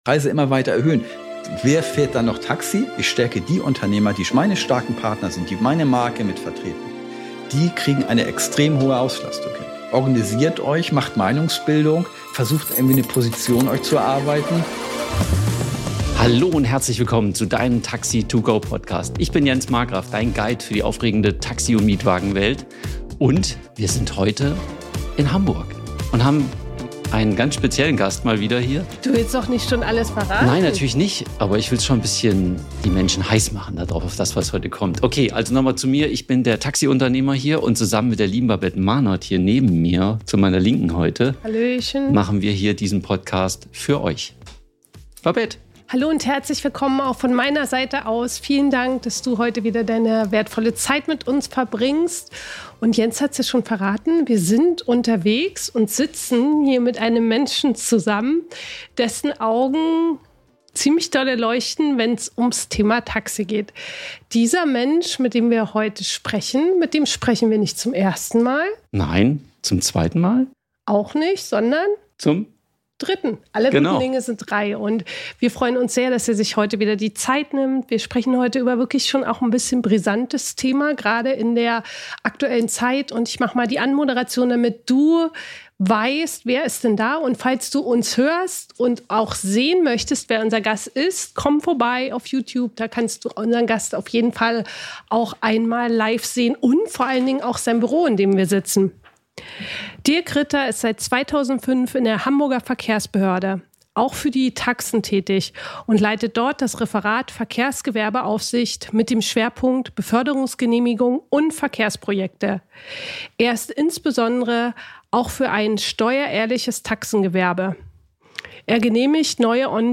- Im Interview mit Verkehrssenator Dr. Anjes Tjarks – Taxi To Go Podcast - Mehr Umsatz, glückliche Mitarbeiter & Geheimnisse aus der Personenbeförderung – Lyssna här